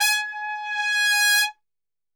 G#3 TRPSWL.wav